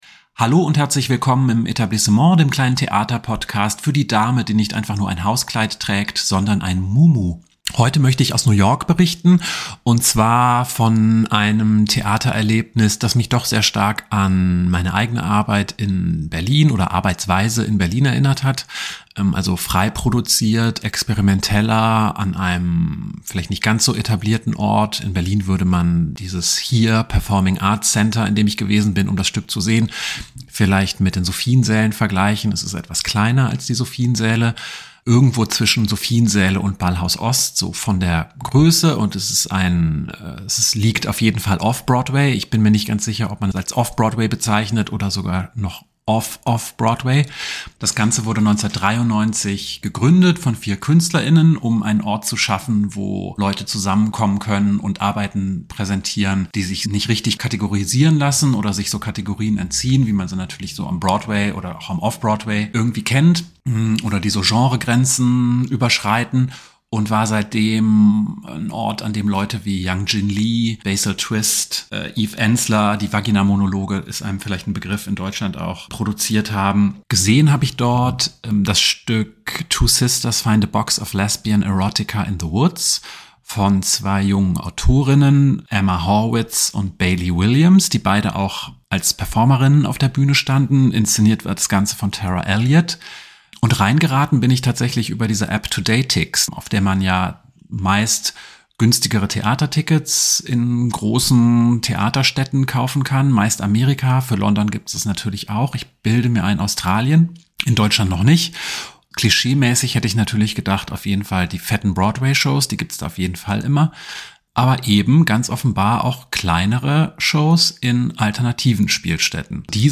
Ein Gespräch